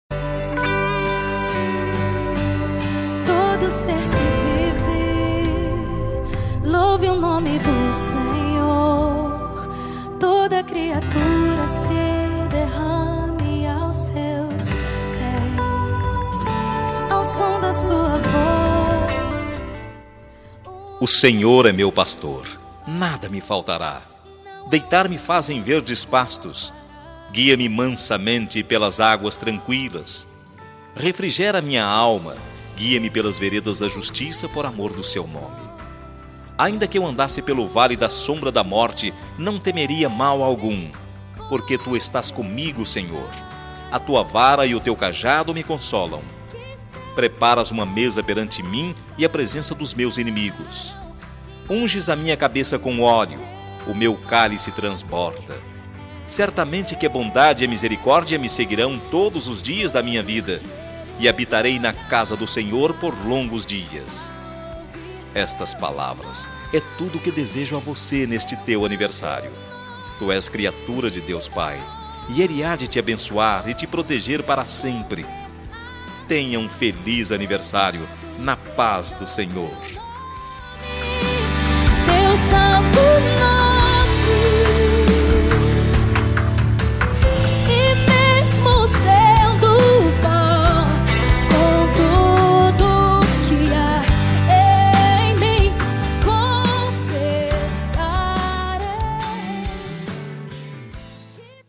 Voz Masculina
Código: 040407 – Música: Teu Santo Nome – Artista: Gabriela Rocha